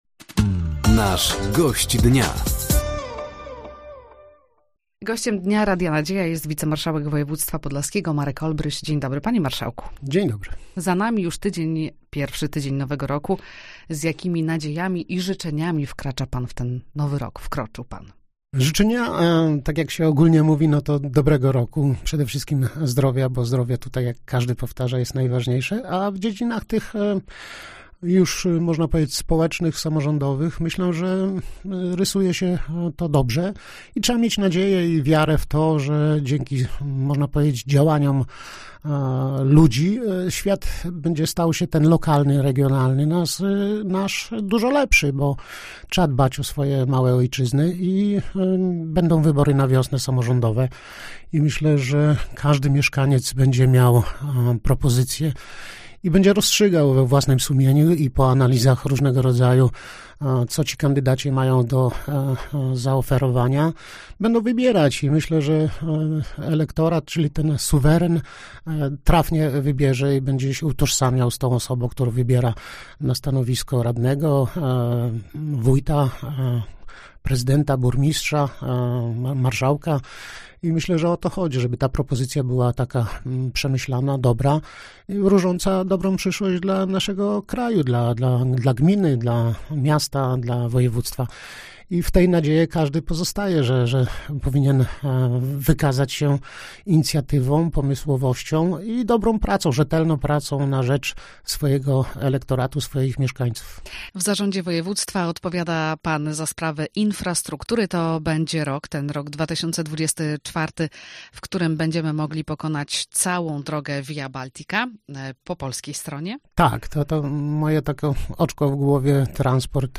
Gościem Dnia Radia Nadzieja był wicemarszałek województwa podlaskiego, Marek Olbryś. Rozmowa dotyczyła między innymi planów rozwojowych na obecny rok, a przede wszystkim transportu w województwie i wyborów samorządowych.